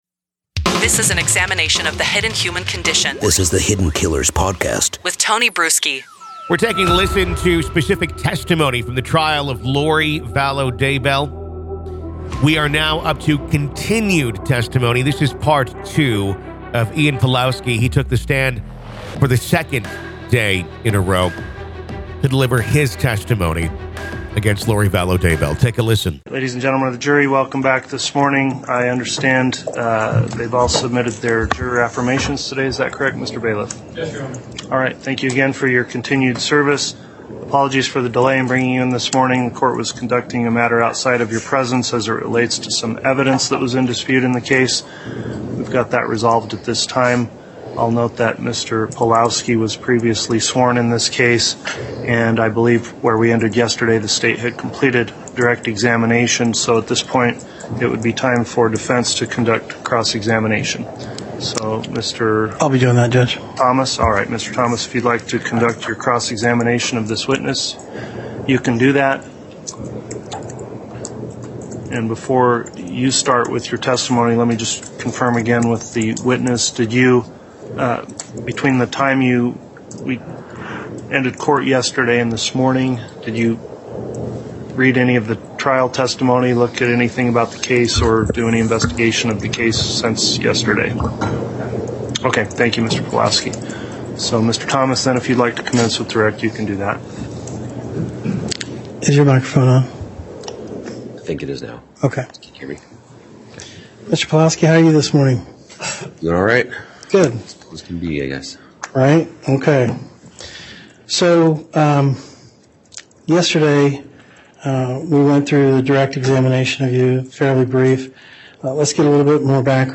The Trial Of Lori Vallow Daybell | Full Courtroom Coverage